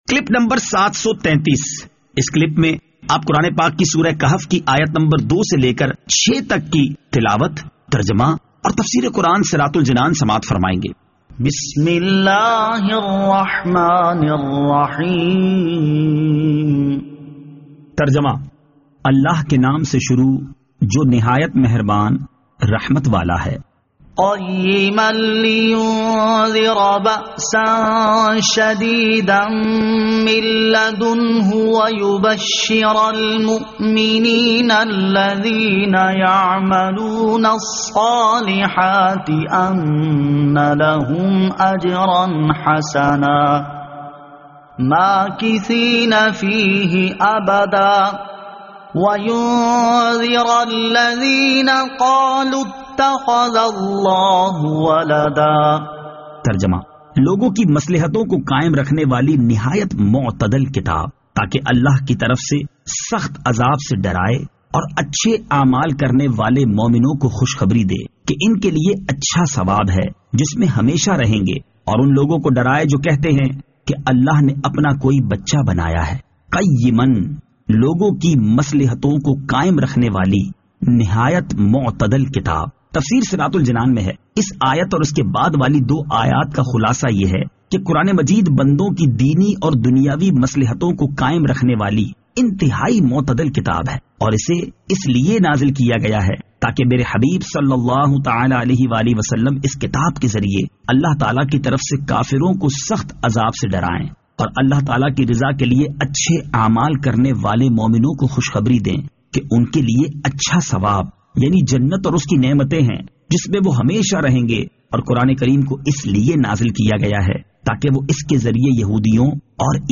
Surah Al-Kahf Ayat 02 To 06 Tilawat , Tarjama , Tafseer